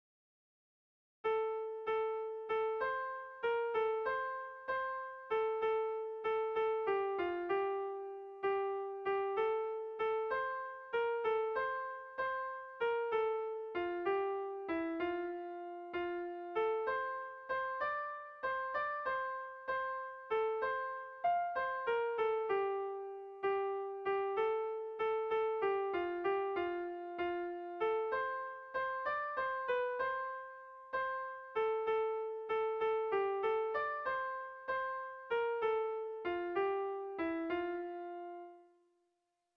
Kontakizunezkoa
Hamarreko txikia (hg) / Bost puntuko txikia (ip)
ABDE.